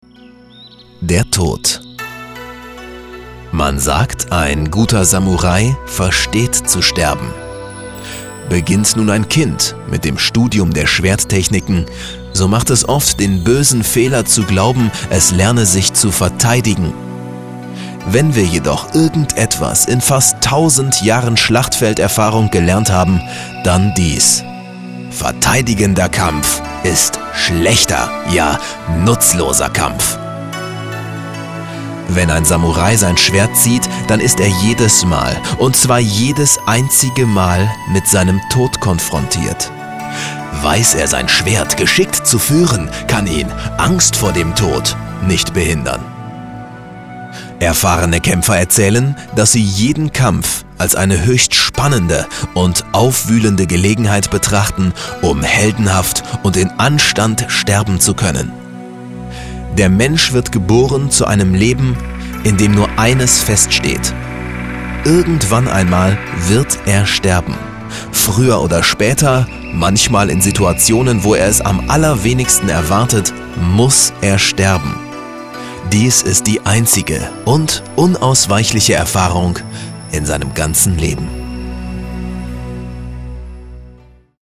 Stimme mit Wiedererkennungswert, großer Flexibilität und Zuverlässigkeit. Eigenes Studio mit MusicTaxi und Aptx.
Sprecher deutsch.
Sprechprobe: eLearning (Muttersprache):
german voice over artist